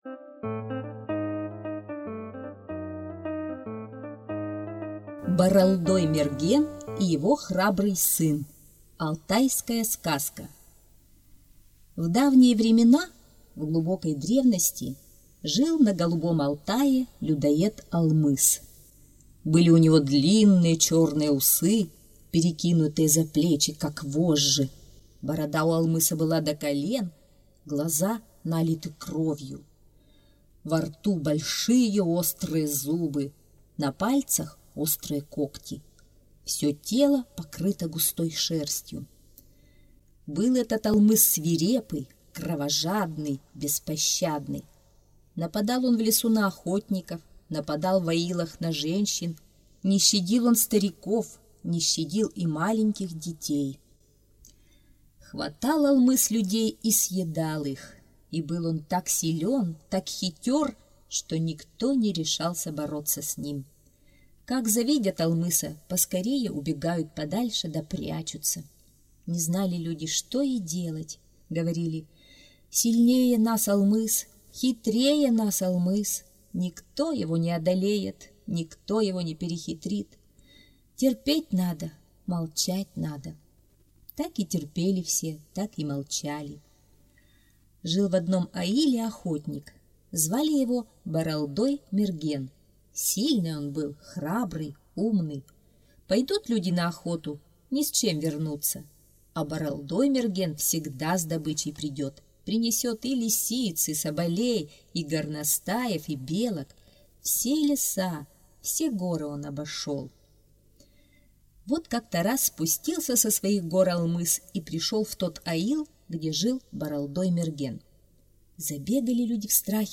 Боролдой-Мерген и его храбрый сын - алтайская аудиосказка.